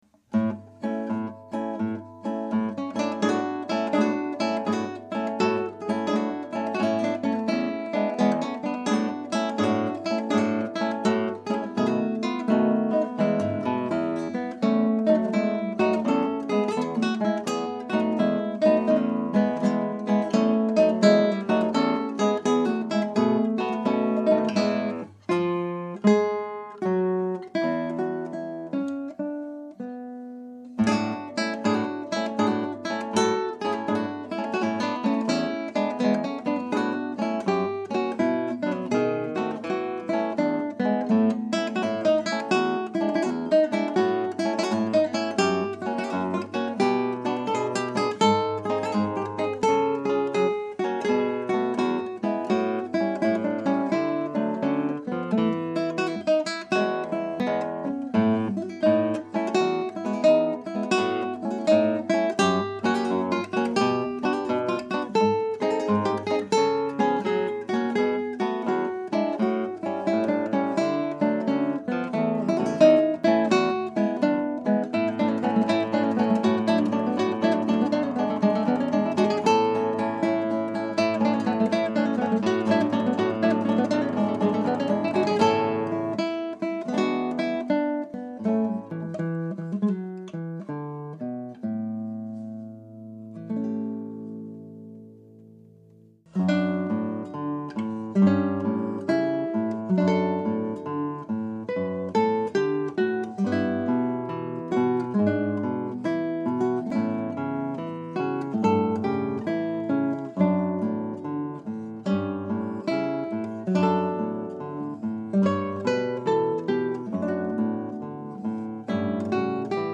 Scraps from the Operas arranged for Two Guitars
Scrap 1: Tempo di Barcarolle.
Scrap 2 (1:36): Adagio.
Scrap 3 (2:29): Allegretto.